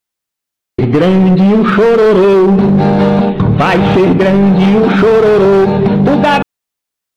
vai ser grande o chororo Meme Sound Effect